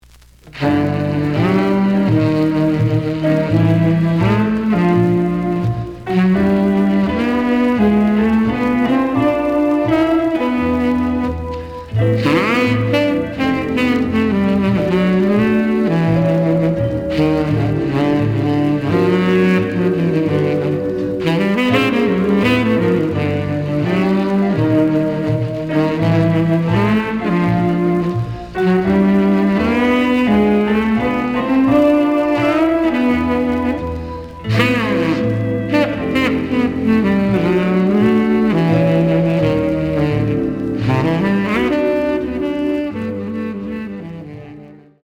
The audio sample is recorded from the actual item.
●Genre: Jazz Other
Looks good, but slight noise on both sides.)